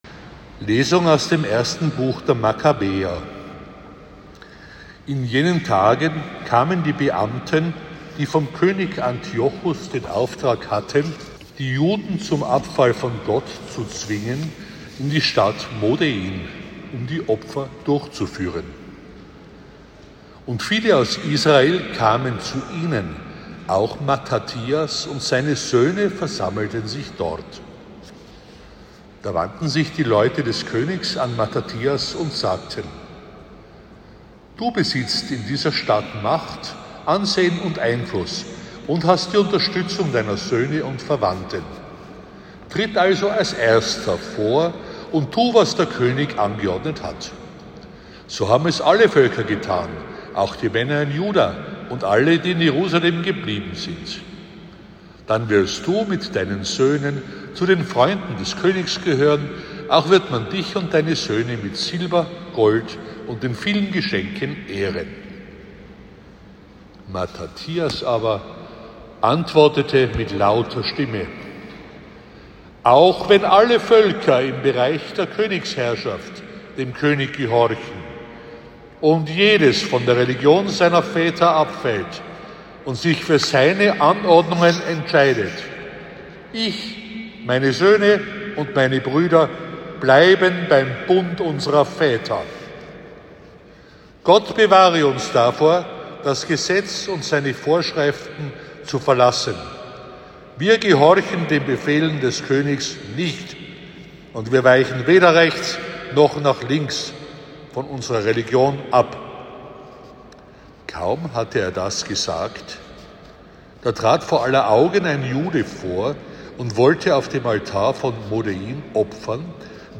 Die heutige Lesung ist lange – mehr als drei Minuten.